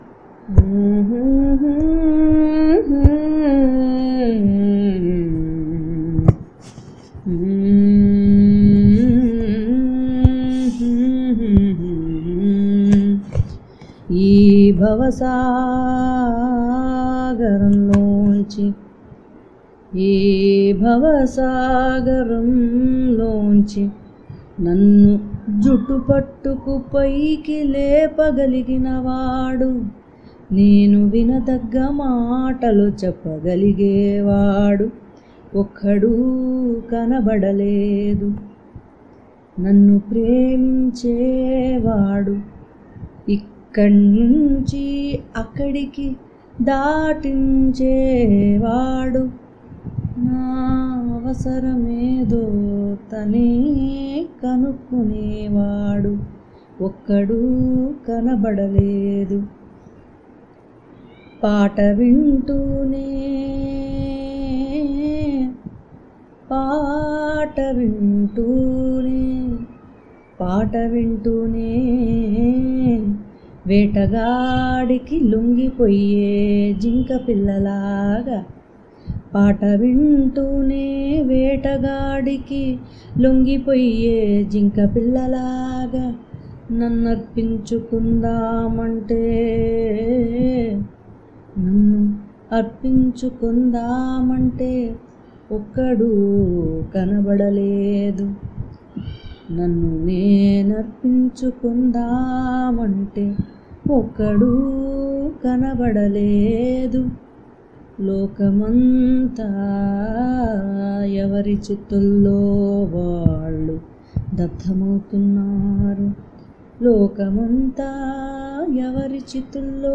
కబీర్ దోహాలకి నా వచన అనువాదాల్ని ఆమె ట్యూన్ చేసి నాకు పంపించినప్పుడు నన్ను సంభ్రమం ముంచెత్తింది.
ఈ గానం వినగానే నాకు చాలా ఏళ్ళ కిందట తిరువాచకాన్ని ఎవరో గానం చెయ్యగా నేను విన్నది గుర్తొచ్చింది.